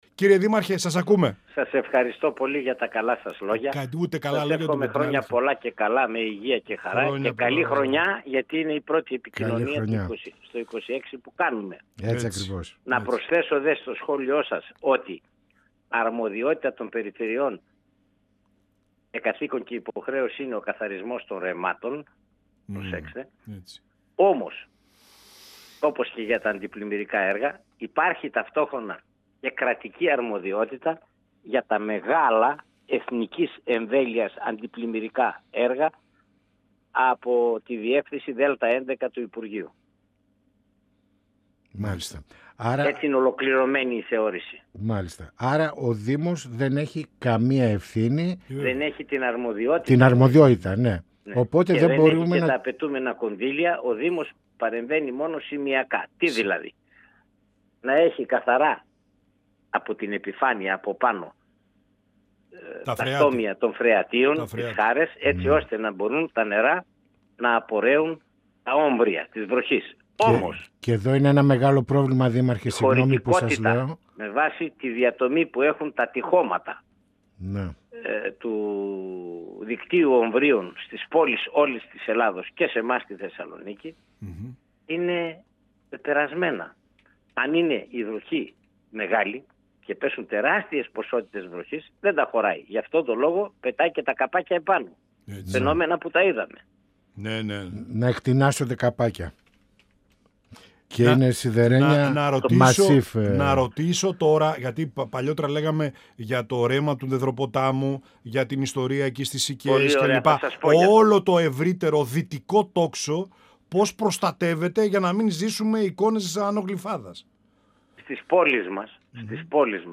Στην πορεία των έργων αντιπλημμυρικής προστασίας στο πολεοδομικό συγκρότημα Θεσσαλονίκης, τη διευθέτηση και τον καθαρισμό των χειμάρρων, καθώς και στα κρούσματα αυθαίρετης δόμησης μέσα στα ρέματα αναφέρθηκε ο Δήμαρχος Αμπελοκήπων-Μενεμένης και Πρόεδρος της Κ.Ε.Δ.Ε Λάζαρος Κυρίζογλου, μιλώντας στην εκπομπή «Πανόραμα Επικαιρότητας» του 102FM της ΕΡΤ3.